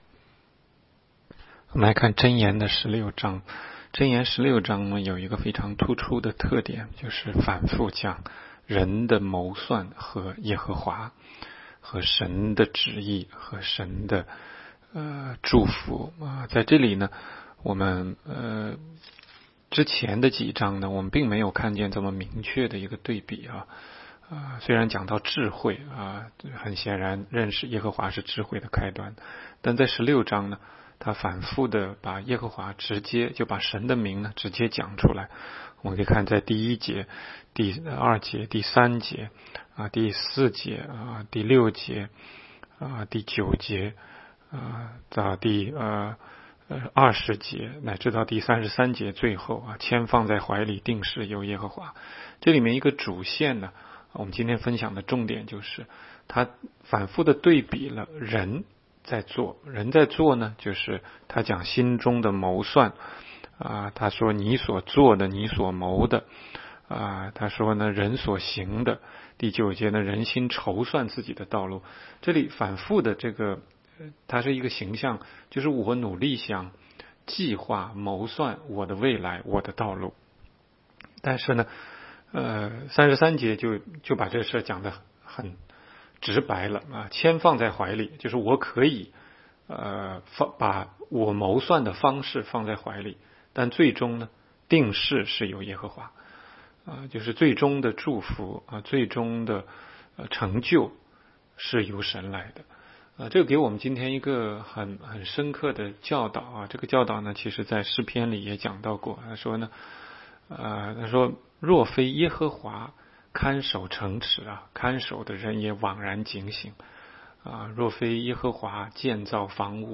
16街讲道录音 - 每日读经 -《 箴言》16章